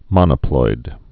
(mŏnə-ploid)